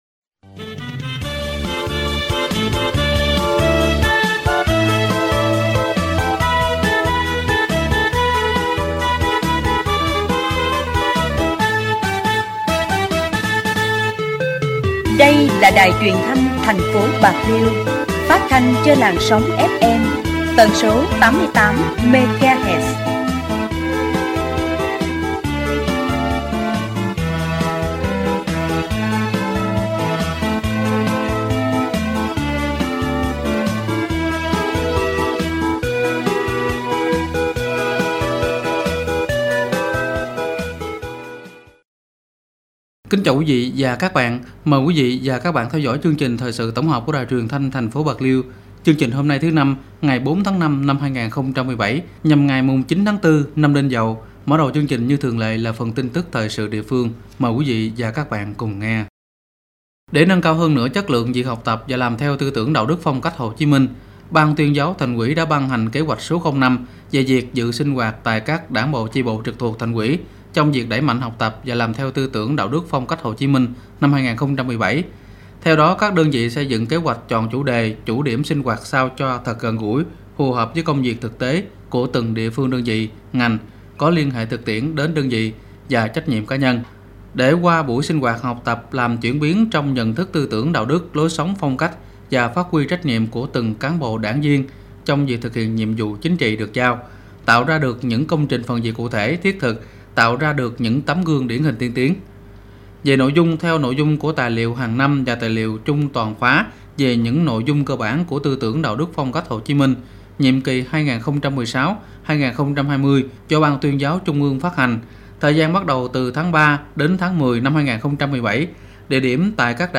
Đài phát thanh bạc liêu: Bí quyết làm giàu từ nuôi tôm – Cách chọn con giống và thức ăn
Dai Bac Lieu - Phat thanh ki 02.mp3